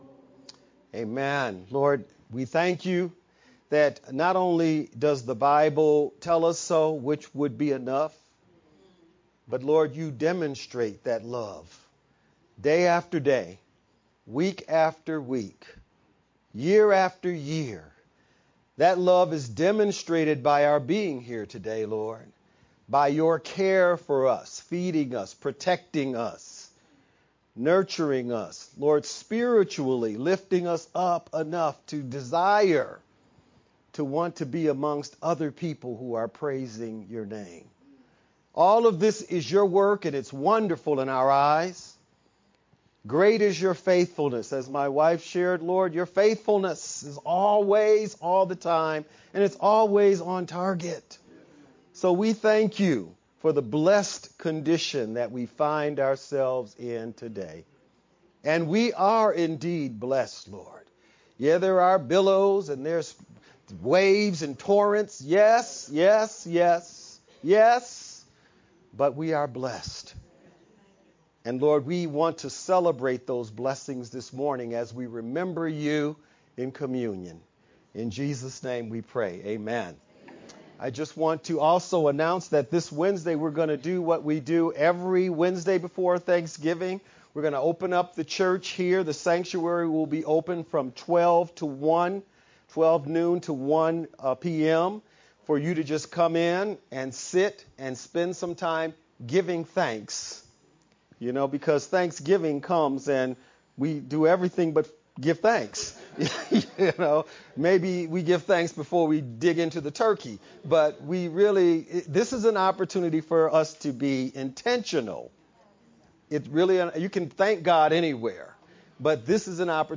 Nov-23rd-edited-VBCC-sermon-only_Converted-CD.mp3